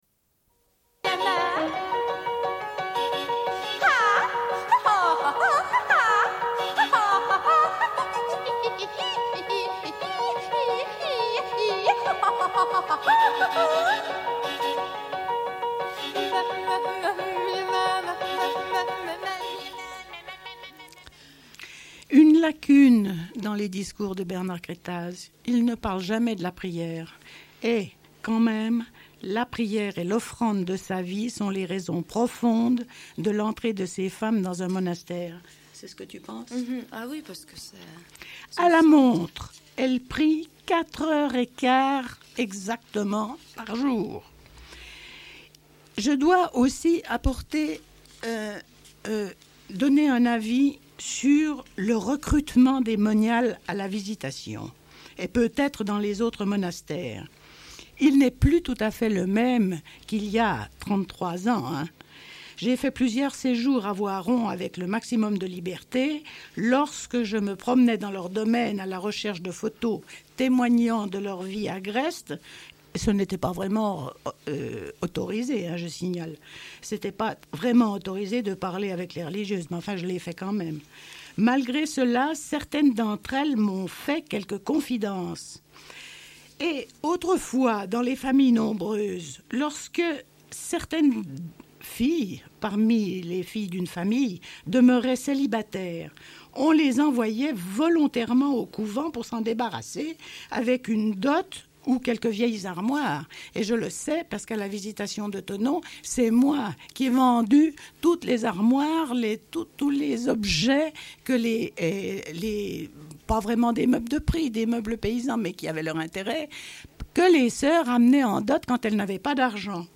Une cassette audio, face A00:31:06